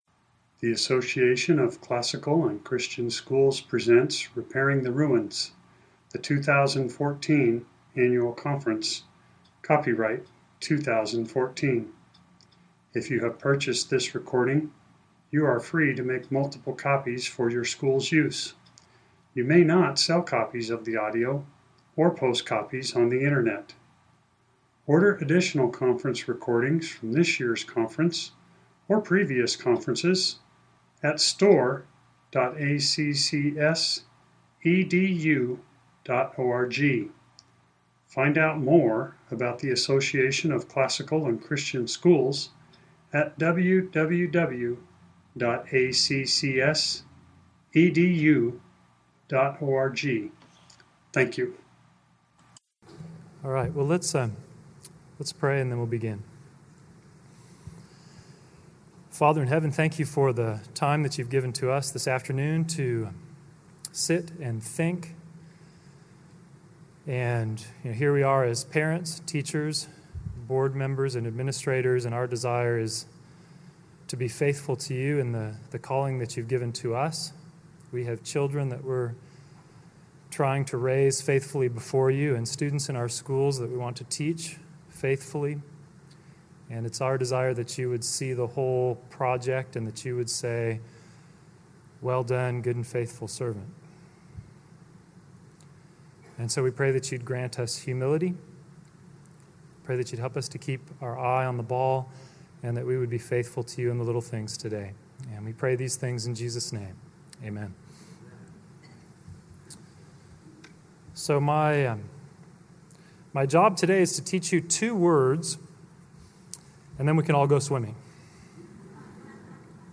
2014 Workshop Talk | 0:54:50 | All Grade Levels, Virtue, Character, Discipline
Jan 18, 2019 | All Grade Levels, Conference Talks, Library, Media_Audio, Plenary Talk, Virtue, Character, Discipline | 0 comments